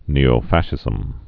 (nēō-făshĭzəm)